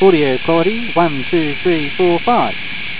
Sound / Audio is measured in a similar way to picture except the "snow" is heard as "shussss" or "hisssss".
This series of 3 second wave files (~23KB each) with decreased levels of "noise" are a rough guide for Q0 to Q5.
~50 audio / ~50 noise   Q4 wave